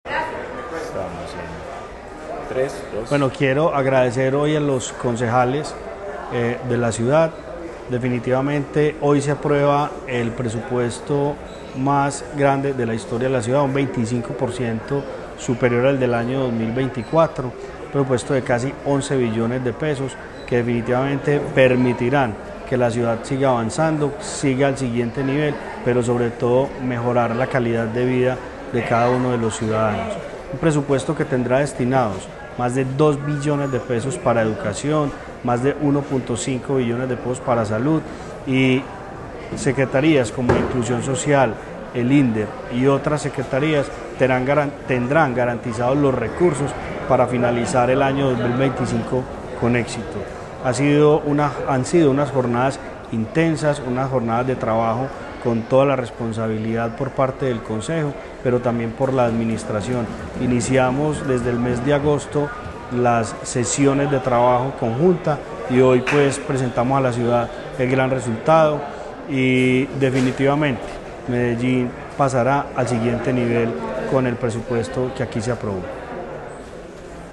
Secretario de Hacienda de Medellín, Orlando Uribe Villa